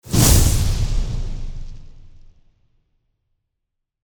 FireballExplosion_1.mp3